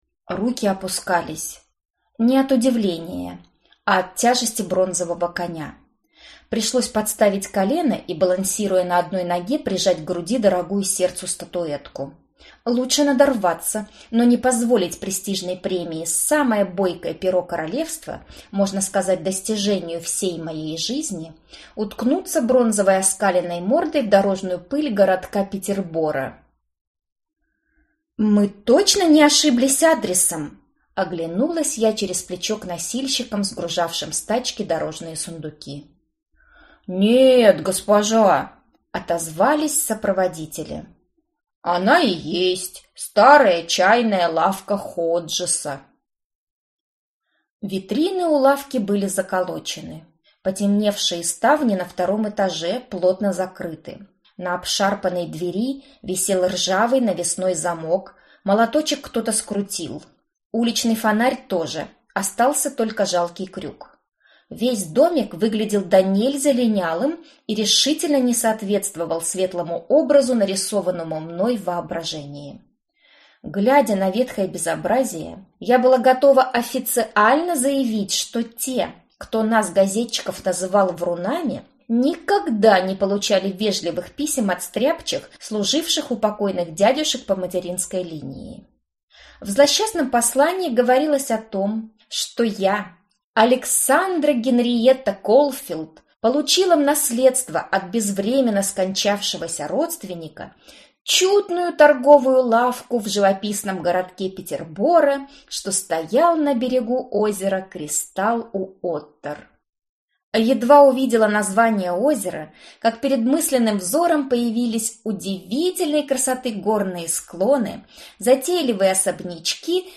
Аудиокнига Пряная штучка | Библиотека аудиокниг